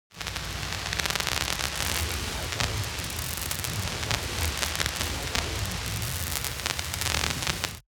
mixkit-electric-welding-and-sparkles-2603.wav